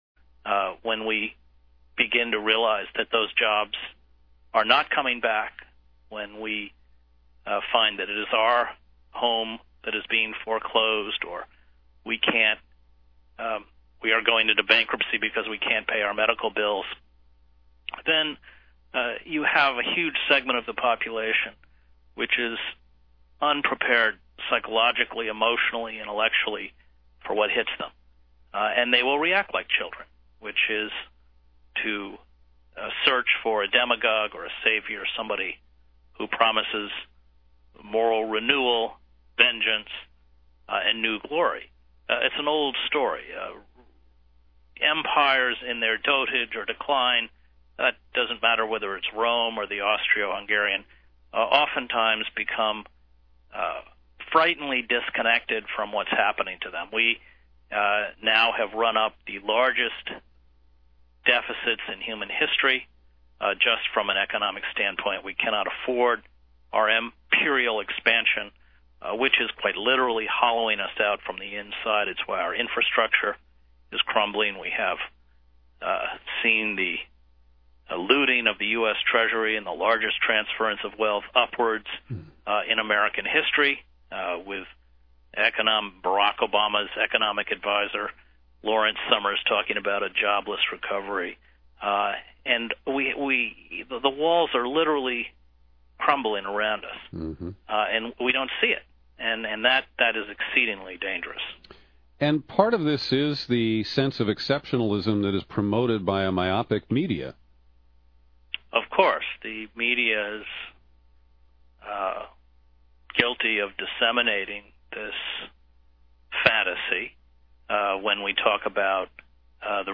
Eight-minute excerpt of the hour-long interview which is available here: